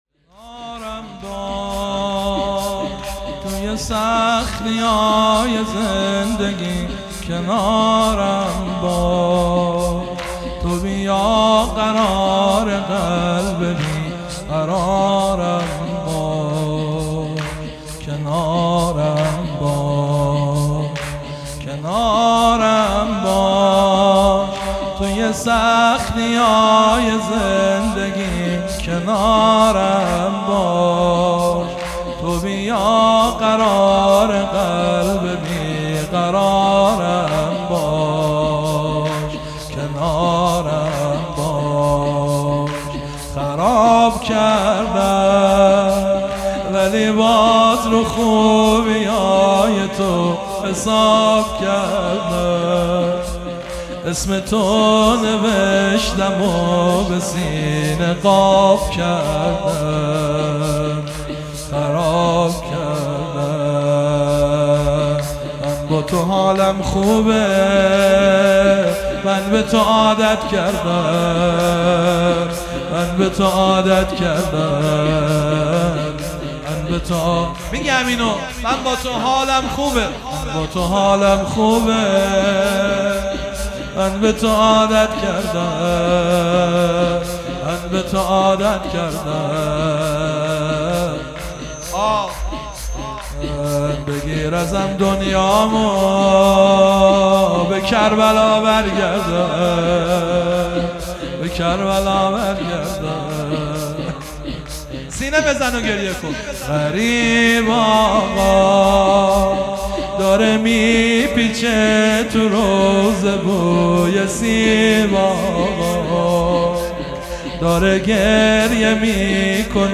شور - کنارم باش